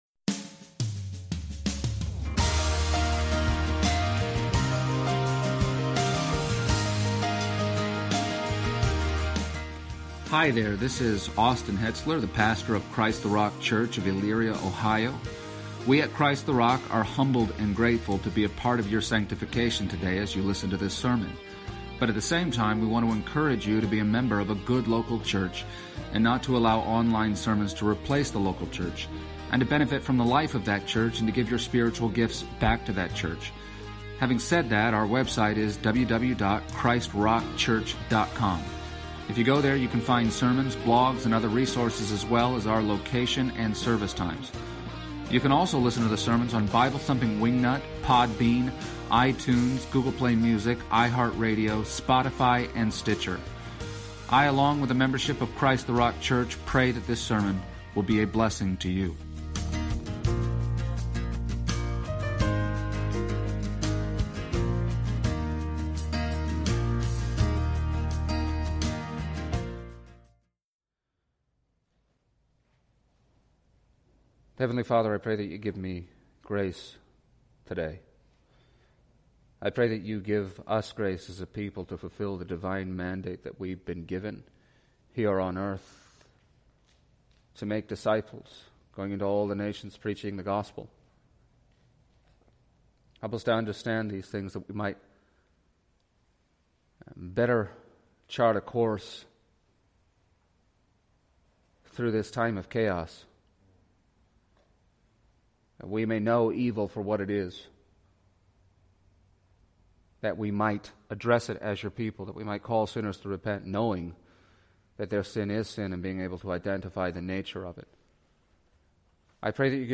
and the Christian Service Type: Sunday Morning %todo_render% « A Godless Government